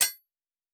Blacksmith 07.wav